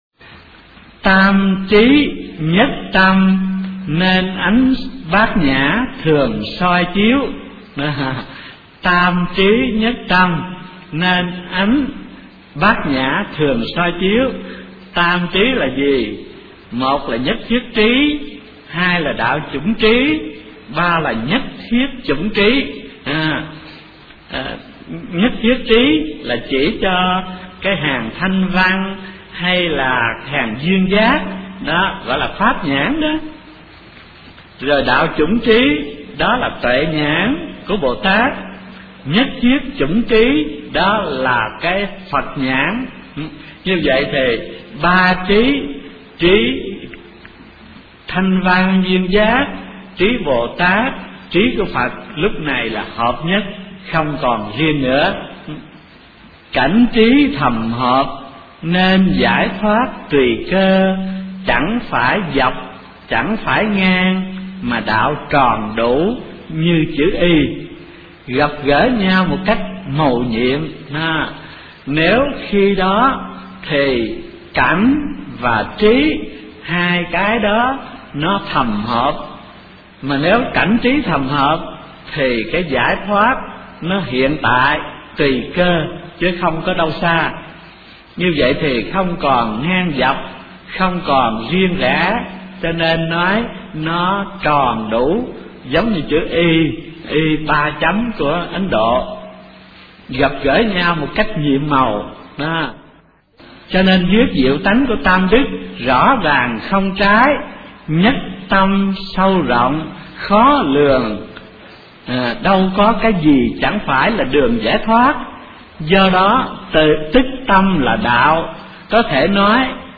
Kinh Giảng Thiền Tông Vĩnh Gia Tập - Thích Thanh Từ